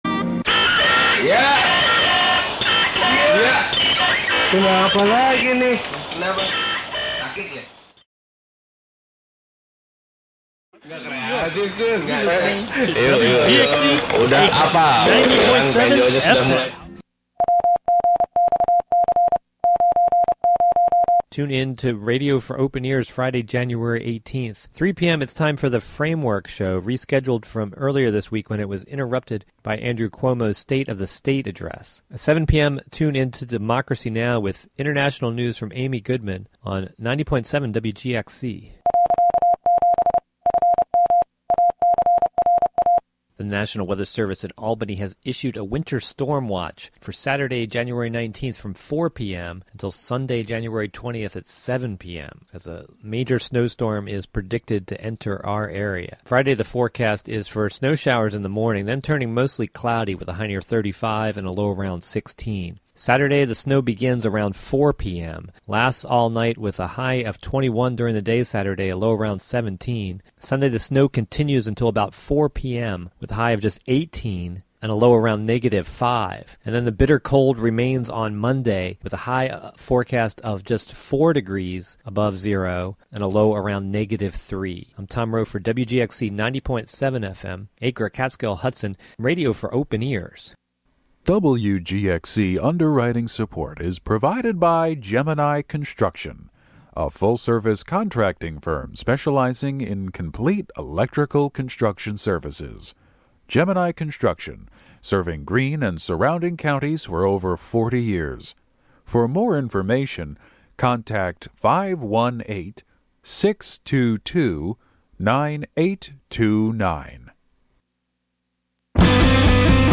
You’re invited to put on your boots and join us every Friday morning as we meander through the wild areas of our modern urban landscape, exploring contemporary and classic Americana, folk, country and elusive material that defies genre.
Broadcast live from the Hudson studio.